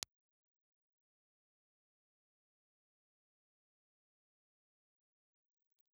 Impulse Response file of STC 4033A microphone in position C
STC4033_Combined_IR.wav
• C = Combined (ribbon and dynamic elements summed together)